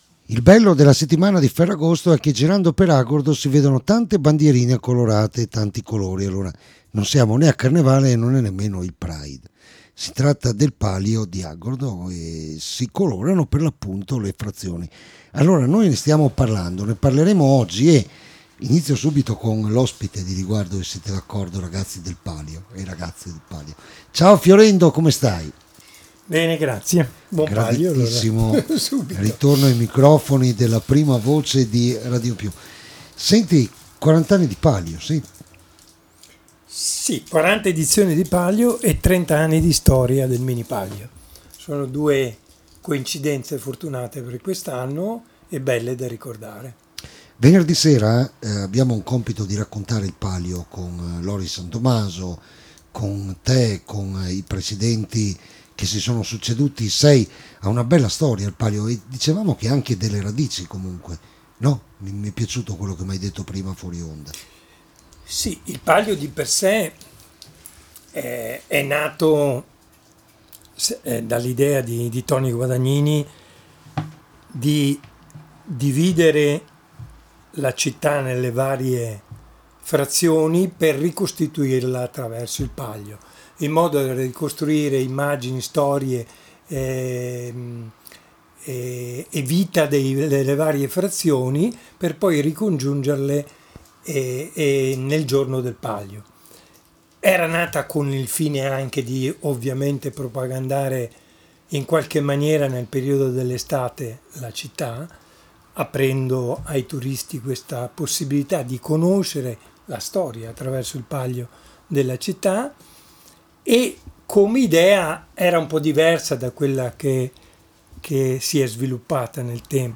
L’INTERVISTA ALLA RADIO…
intervista-palio-di-agordo-1.mp3